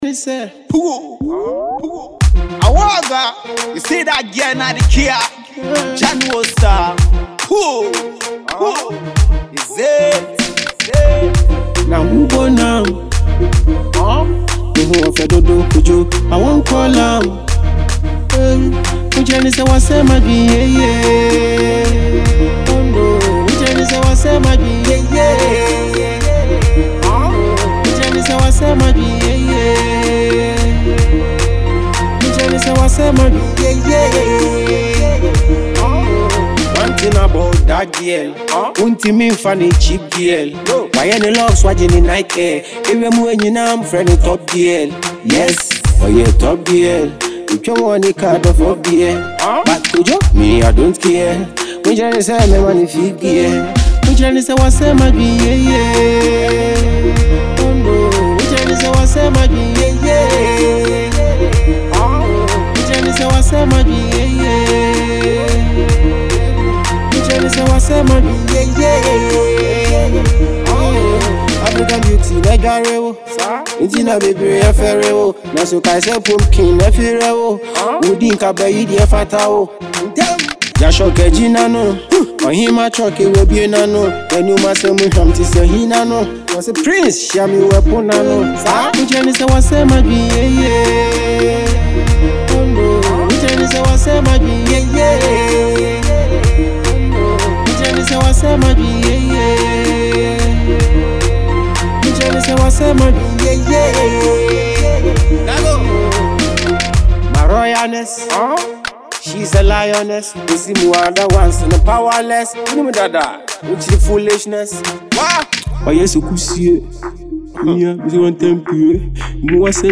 Genre: Afro Beat